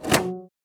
ArrowCrossBowShot-004.wav